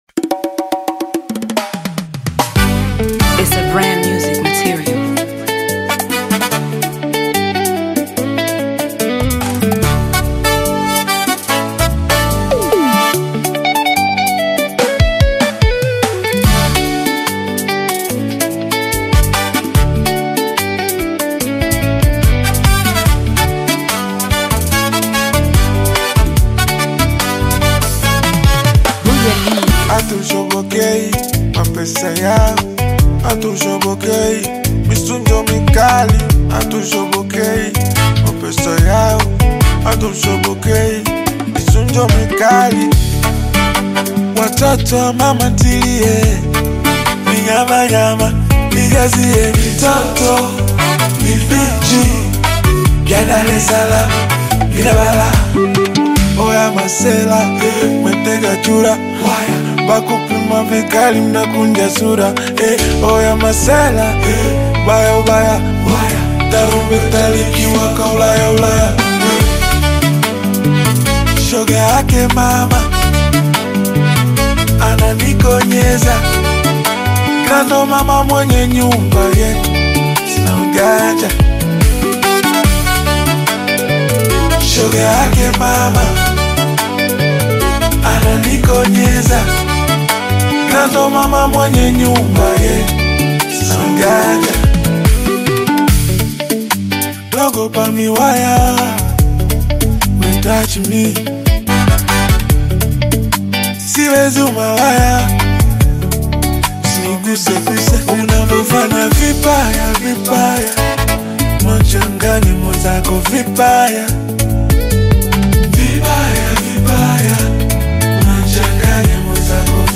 Latest Tanzania Afro-Beats Single (2026)
Genre: Afro-Beats